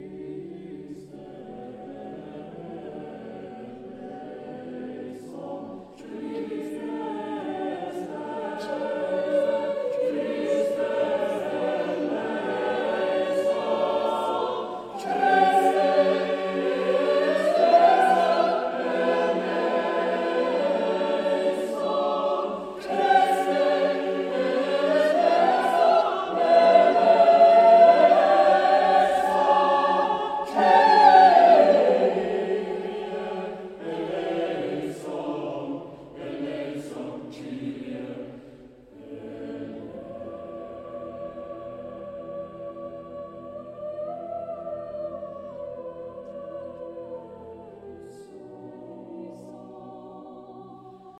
Mass for double choir